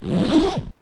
sounds_zipper.ogg